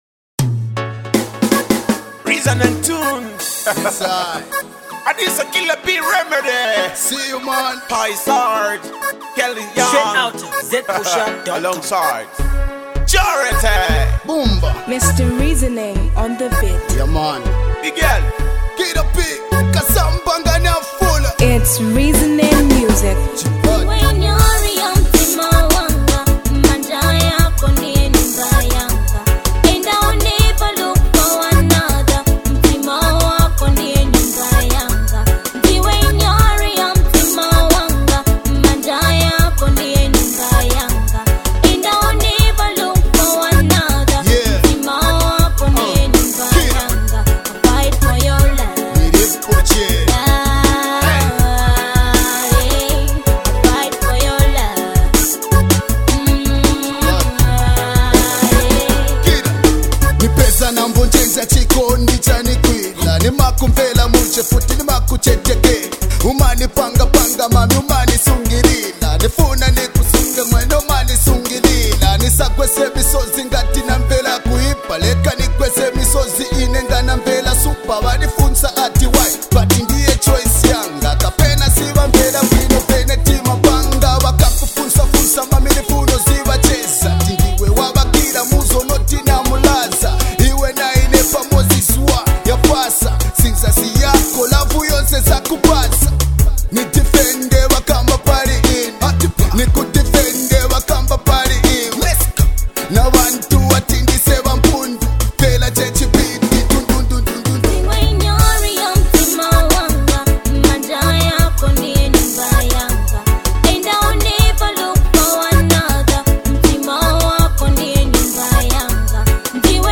RnB song